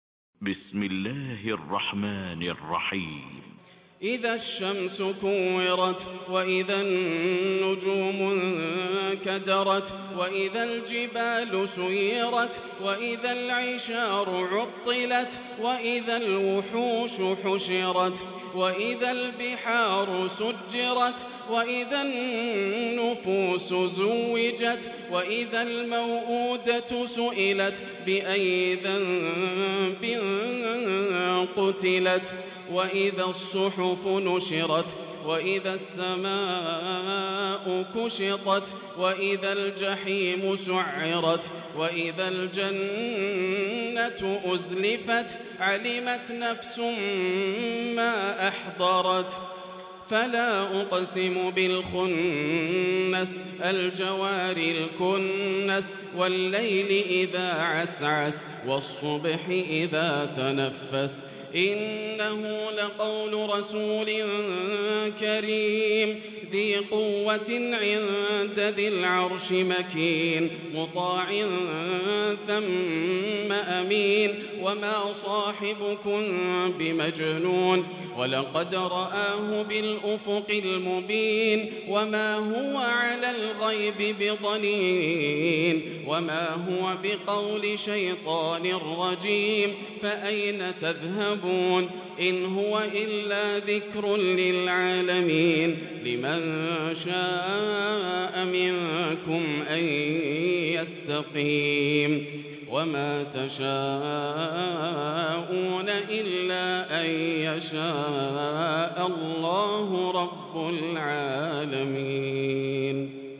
Taraweeh Prayer from the holy Mosque 1445